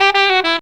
COOL SAX 14.wav